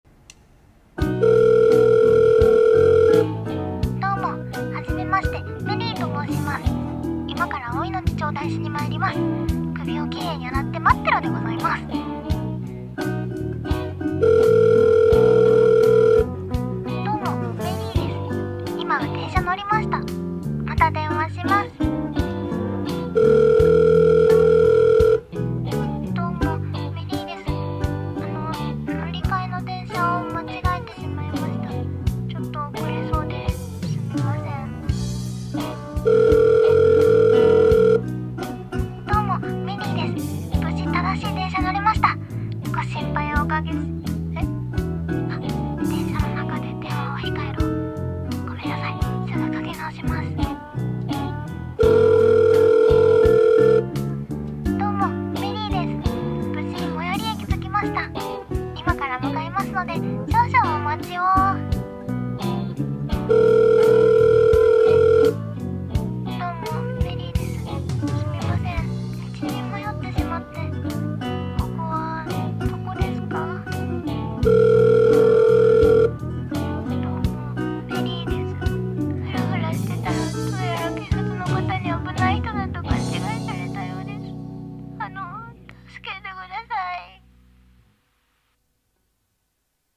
ギャグ声劇台本】どうも、メリーです。